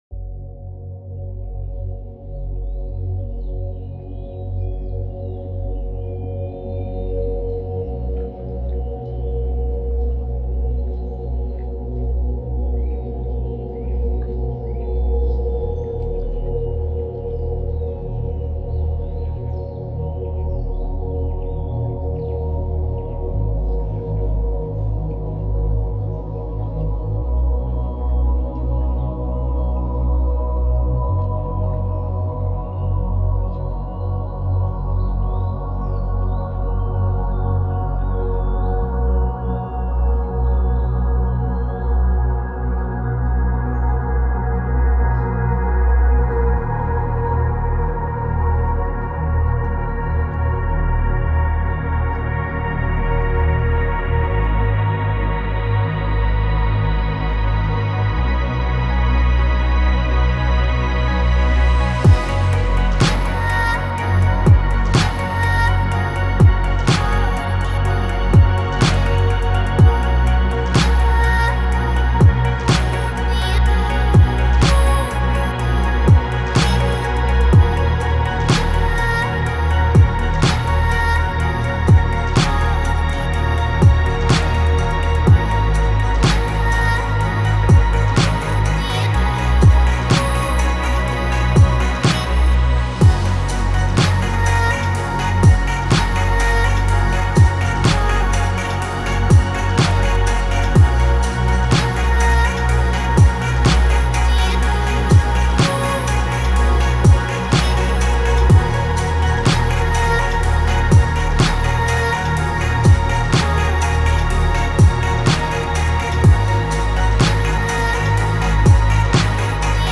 это захватывающая композиция в жанре электронной музыки